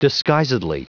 Prononciation du mot disguisedly en anglais (fichier audio)
disguisedly.wav